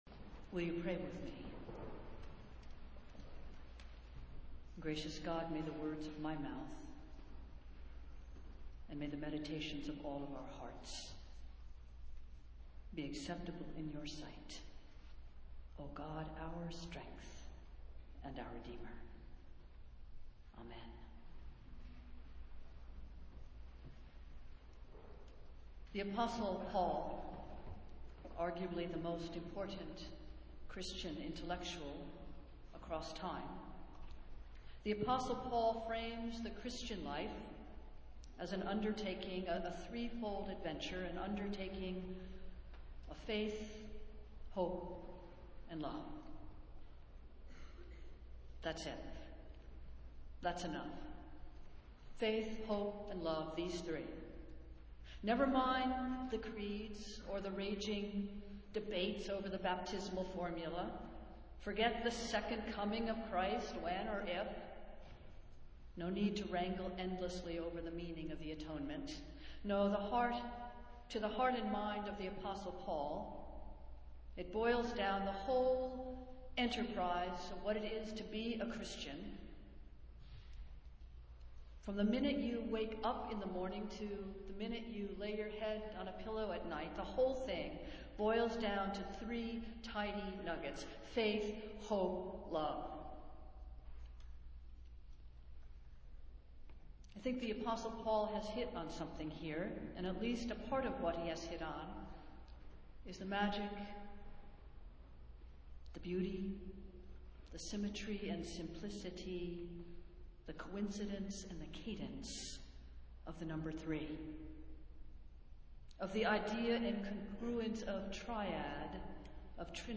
Festival Worship - Fifth Sunday after Epiphany